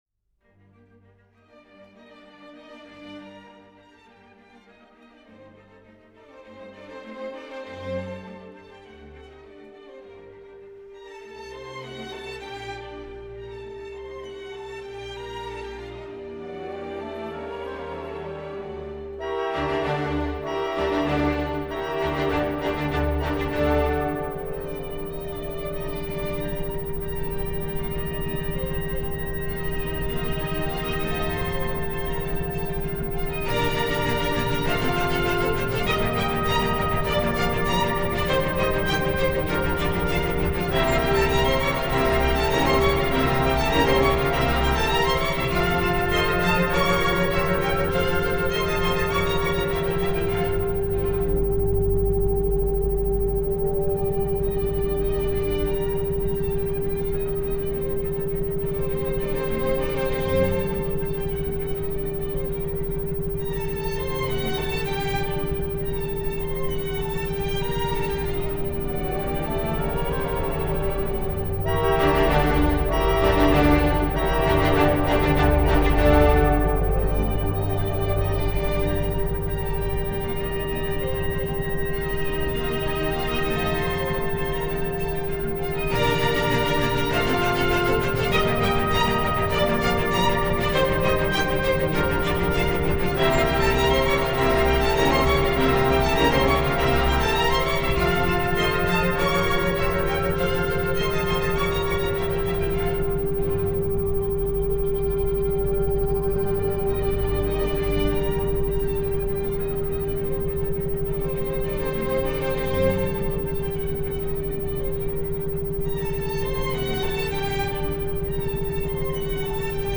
MOZART LOOP MEDITATION Featuring Frequency 396 Mozart-Loop-Meditation This is a therapeutic musical loop of a famous symphonic passage by Mozart. It is combined with a frequency 396Hz, which is said to assist in alleviating guilt and fear. This track runs about 18 minutes and works best at a medium-soft volume, ideally using headphones.
mozart-loop-meditation.mp3